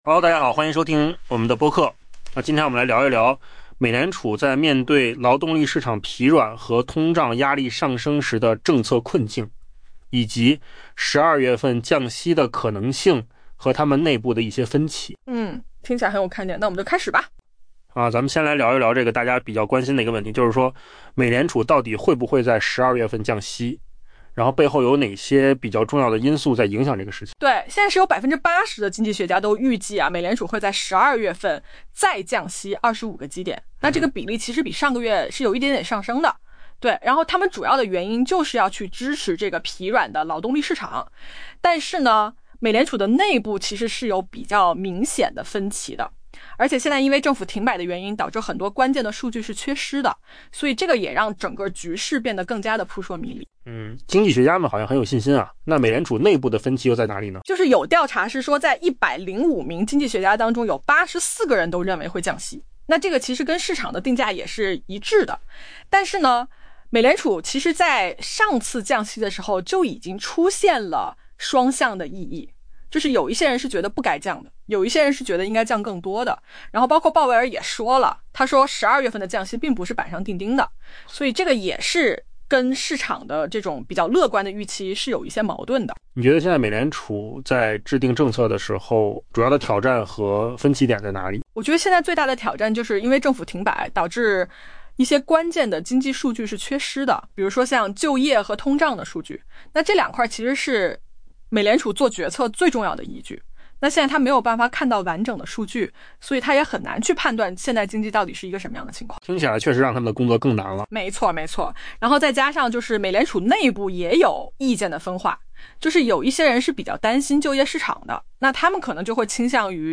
AI 播客：换个方式听新闻 下载 mp3 音频由扣子空间生成 路透社最新调查显示， 80% 的受访经济学家预计，美联储将在下月再次下调关键利率 25 个基点，以支撑日益疲软的劳动力市场。